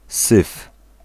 Ääntäminen
IPA: /sɨf/